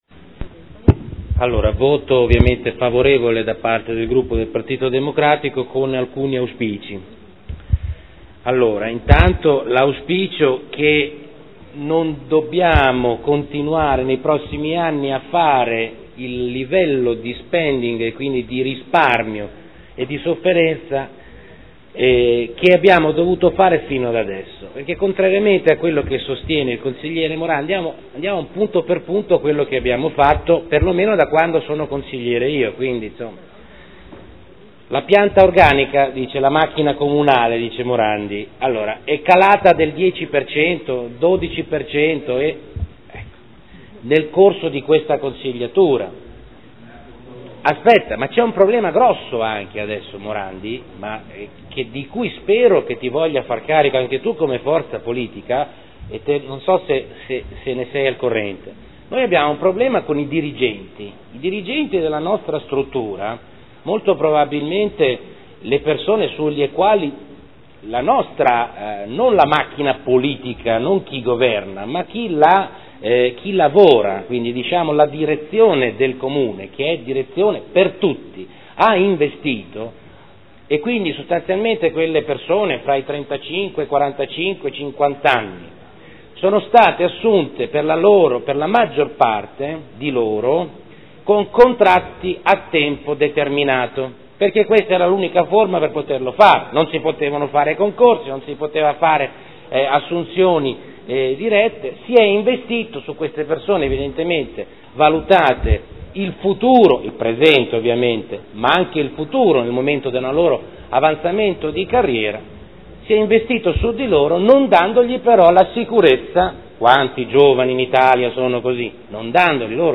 Salvatore Cotrino — Sito Audio Consiglio Comunale